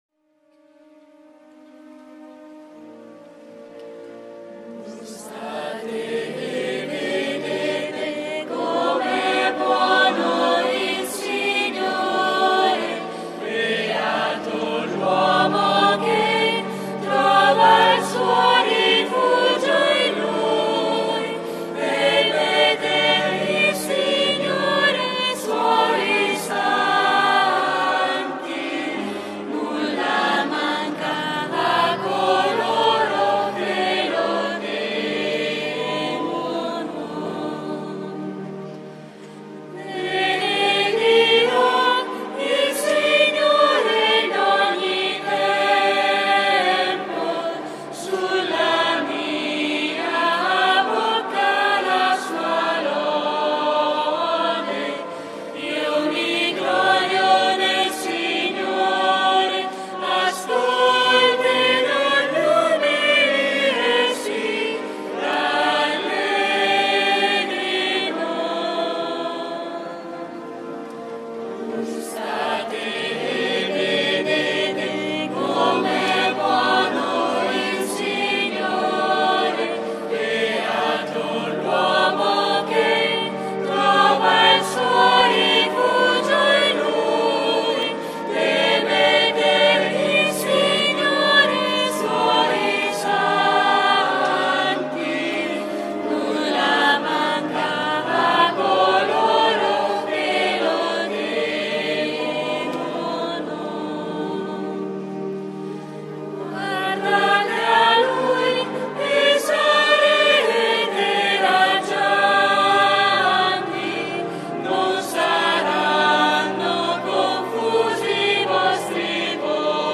III domenica di QUARESIMA - Terza tappa itinerario di Quaresima
canto: